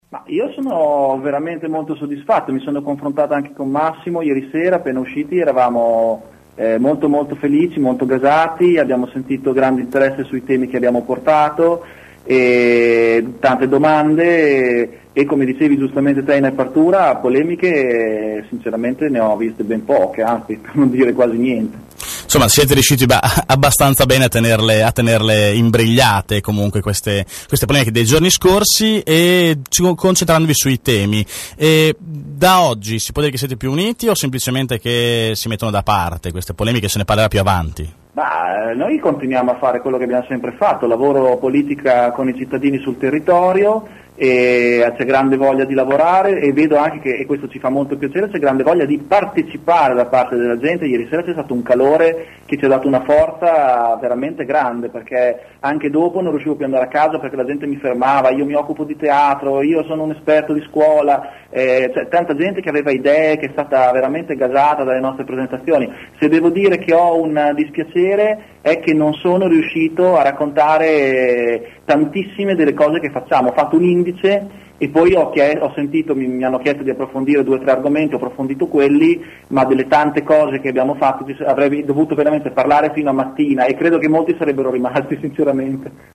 “L’assemblea è andata bene” è stato il commento degli organizzatori alla fine, ripetuto questa mattina anche da Piazza ai nostri microfoni.